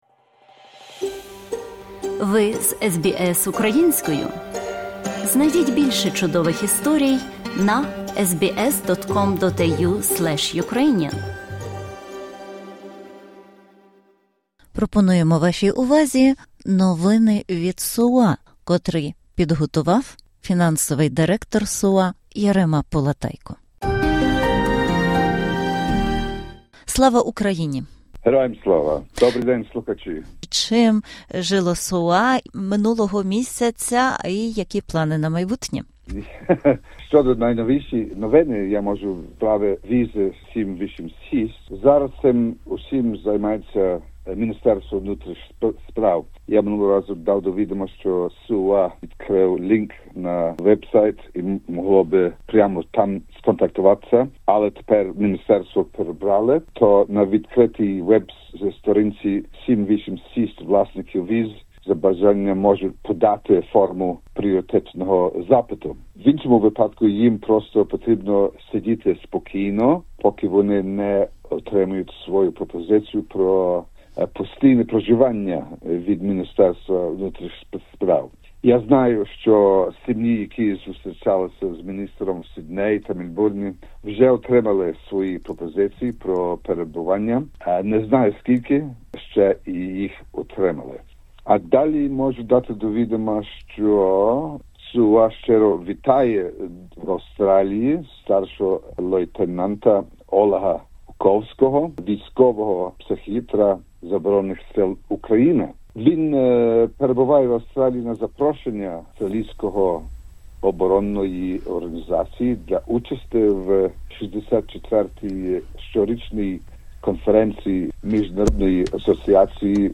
Інтерв’ю